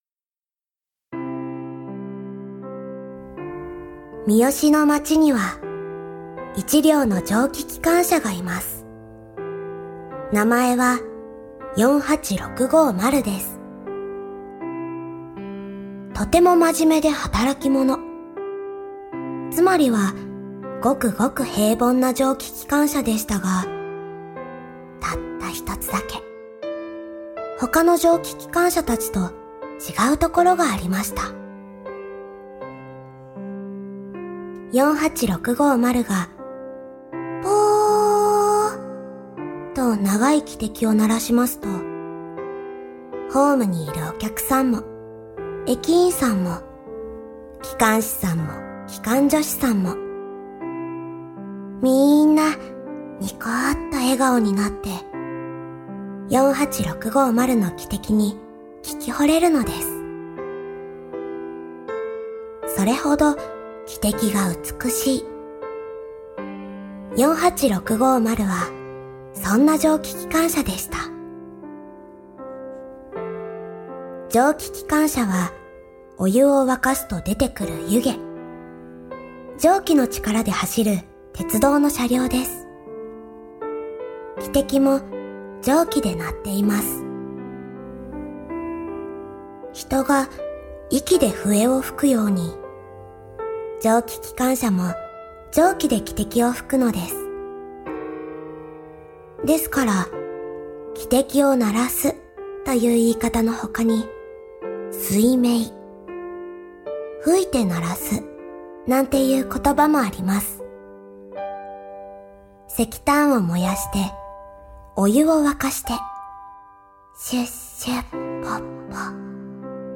akuri_roudoku_01_otameshi.mp3